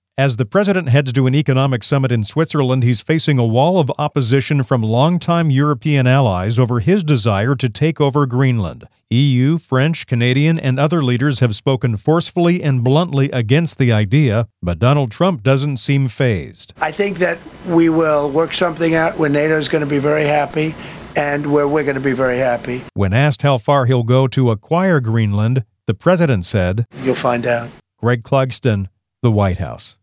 News stories as heard on SRN Radio News.